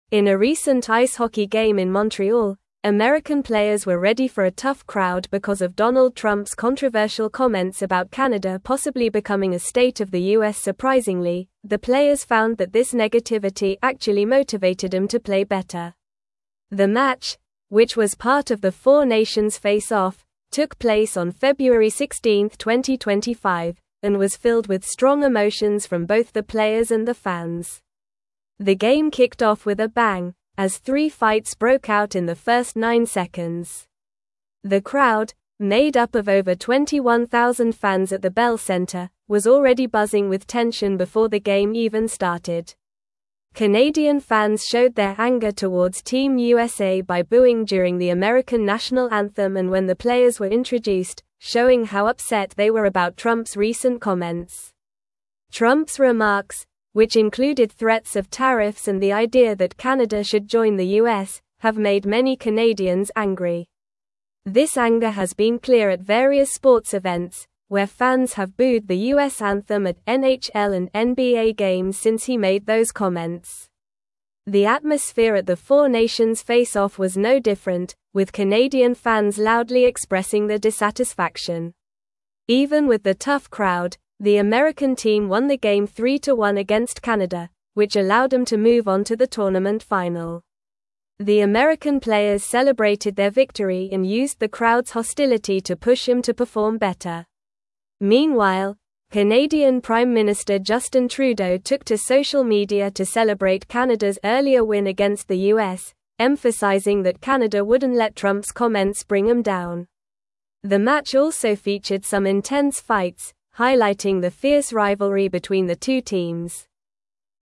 Normal
English-Newsroom-Upper-Intermediate-NORMAL-Reading-Intense-Rivalry-Ignites-During-4-Nations-Face-Off.mp3